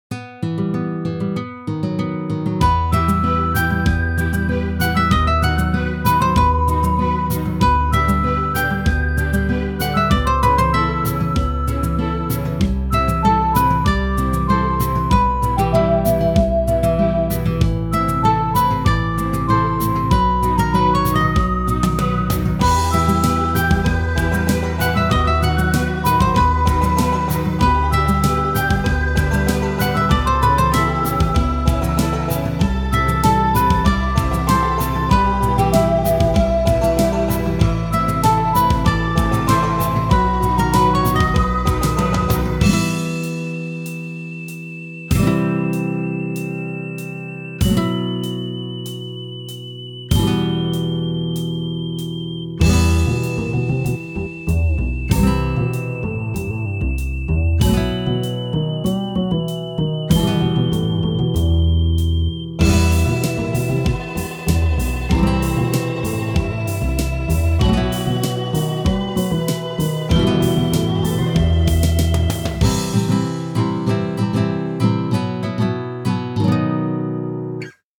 渋い